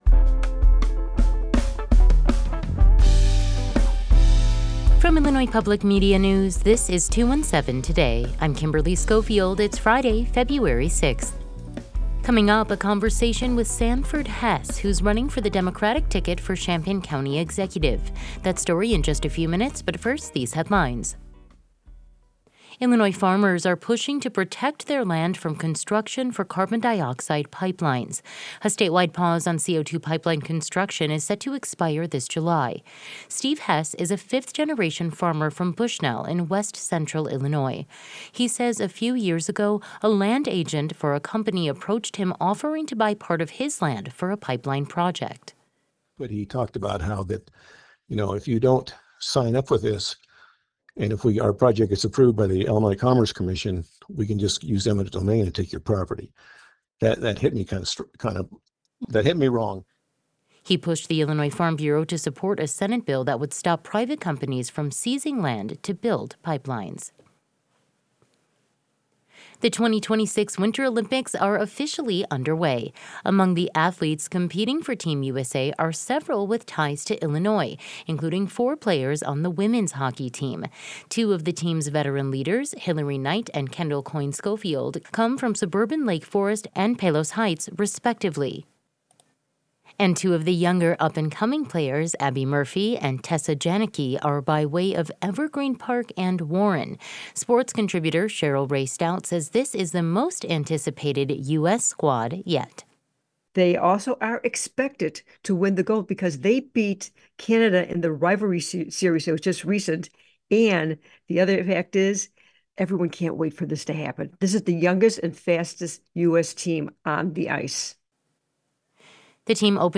Today’s headlines: Illinois farmers are pushing to protect their land from construction for carbon dioxide pipelines. The 2026 Winter Olympics are officially underway.